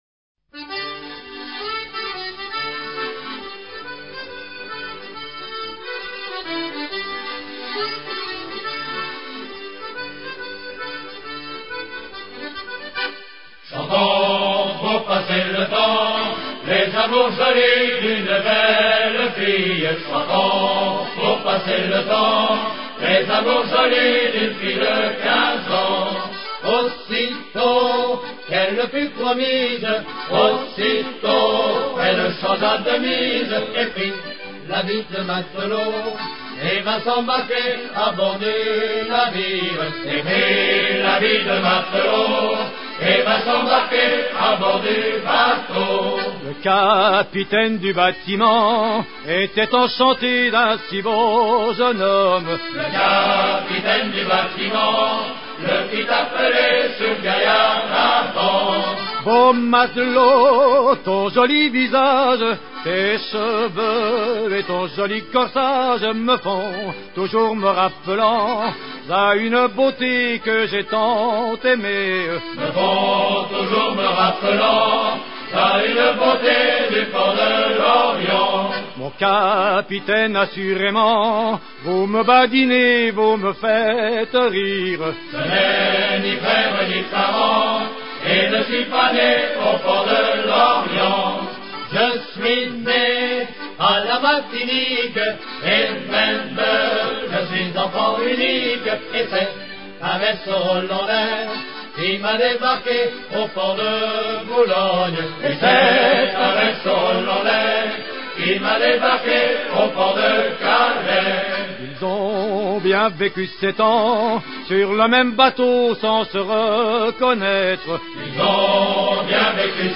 Ballade